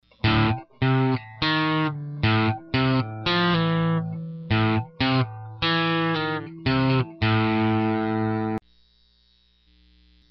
残業して徹夜したため、寝ぼけてギターなんて衝動買いしてみたのだ。
練習1日目2日目でネットにアップするという暴挙に出てみる。